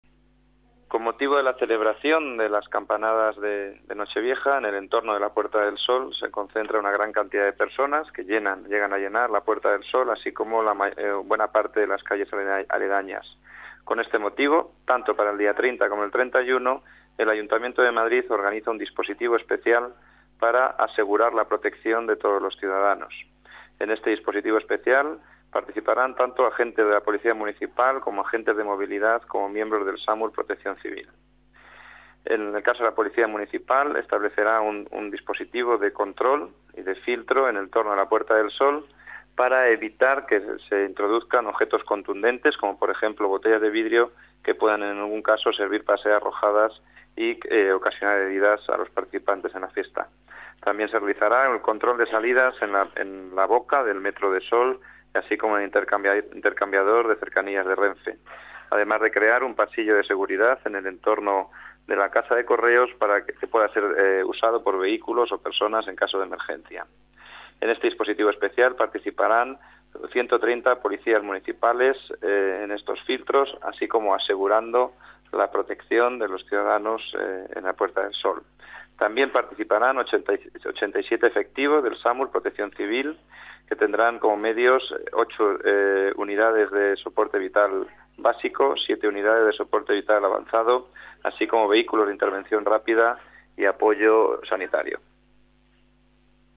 Nueva ventana:Declaraciones de Javier Conde, coordinador general de Seguridad